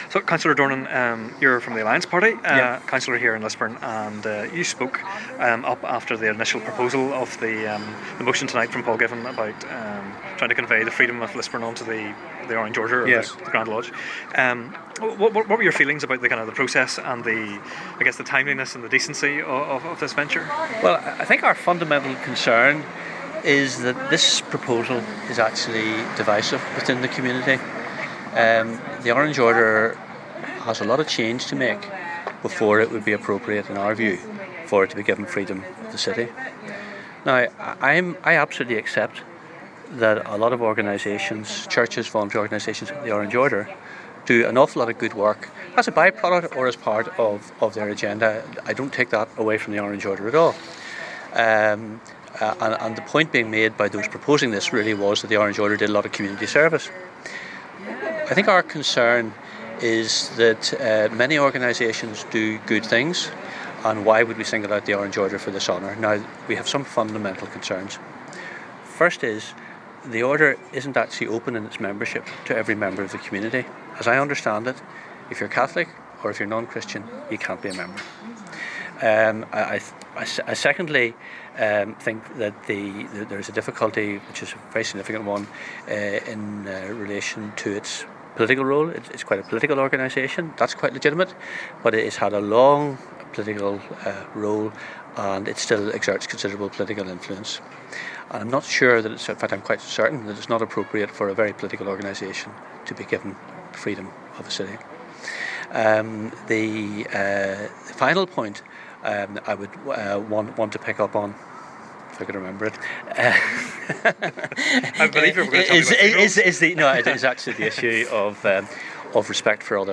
Councillor Dornan (Alliance) on Orange Order freedom motion at Lisburn City Council